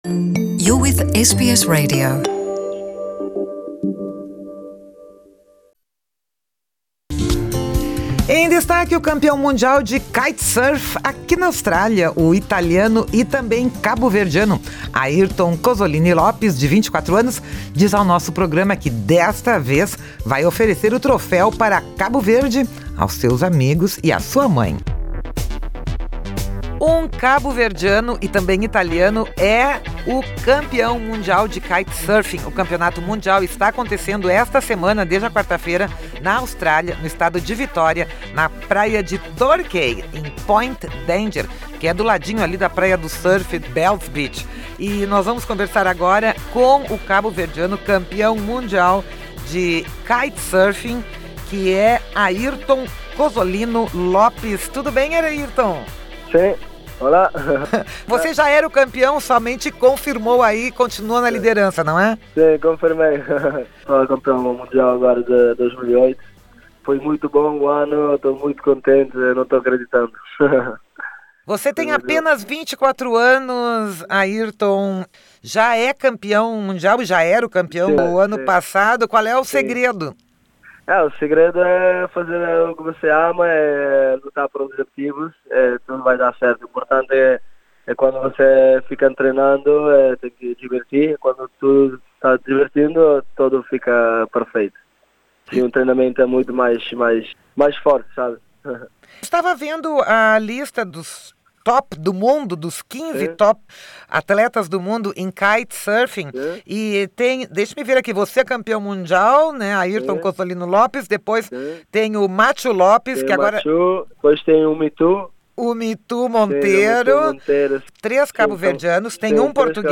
Airton Cozzolino Lopes dá entrevista ao Programa de Língua Portuguesa da Rádio SBS diretamente da praia de Torquai, no estado de Vitória, onde acontece a final do campeonato mundial de kitesurfing, de 12 a 16 de dezembro.